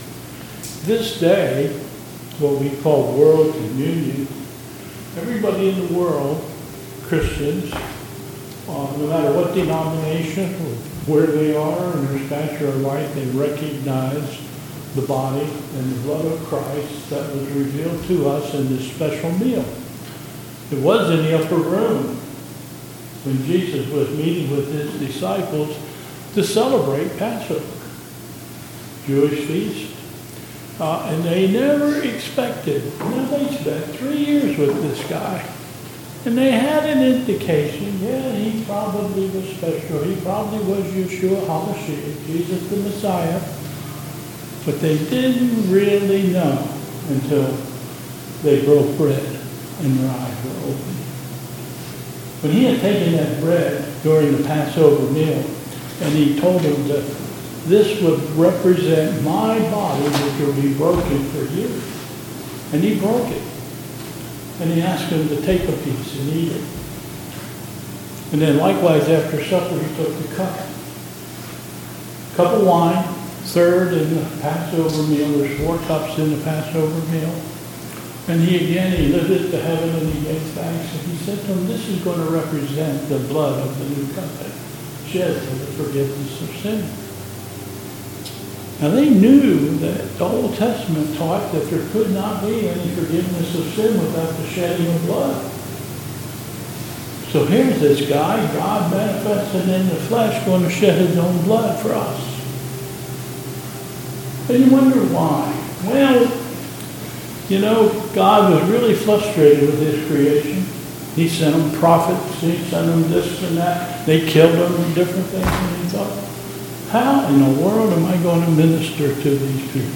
The Message